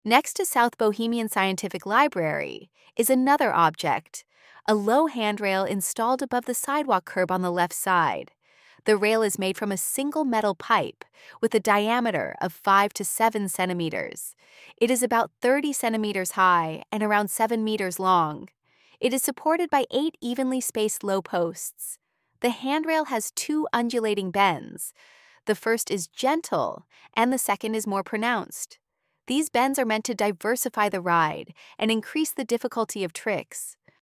AUDIODESCRIPTION LOW HANDTRAIL